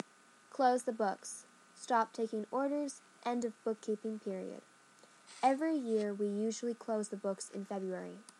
英語ネイティブによる発音はこちらです。